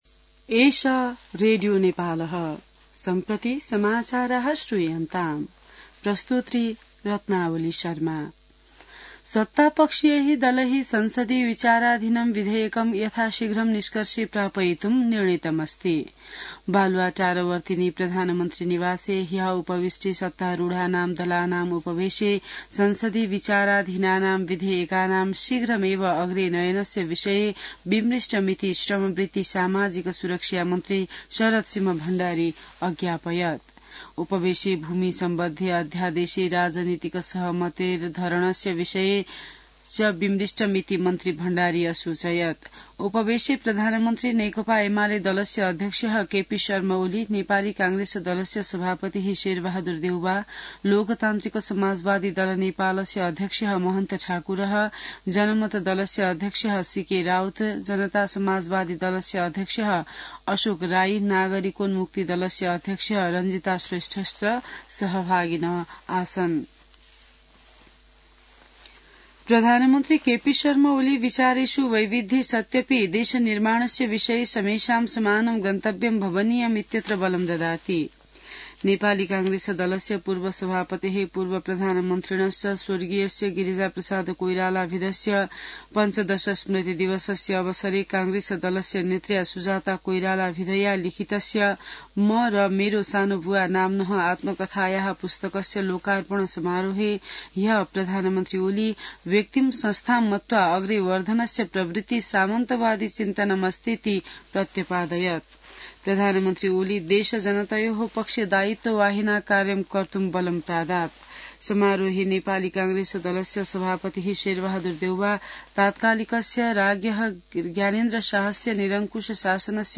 संस्कृत समाचार : १३ चैत , २०८१